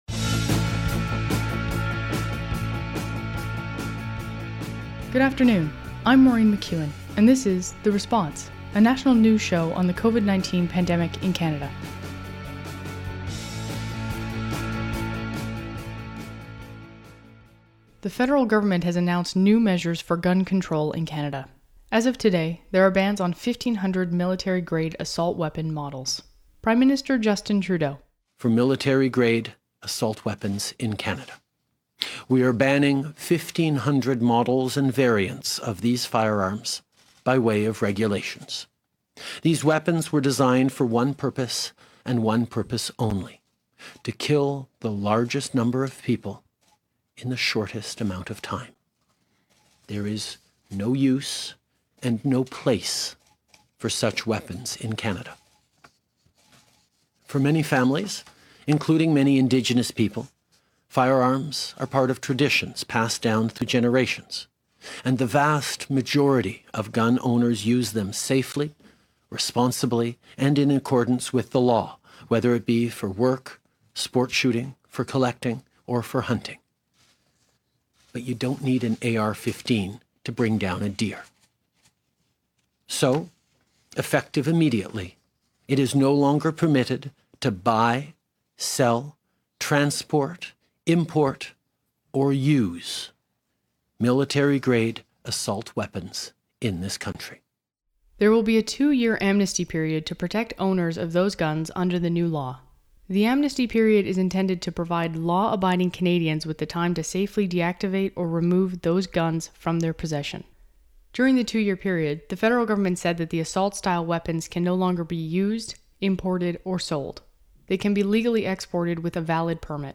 National News Show on COVID-19
We hear from the prime minister and public safety minister.
Credits: Audio clips: Canadian Public Affairs Channel.
Type: News Reports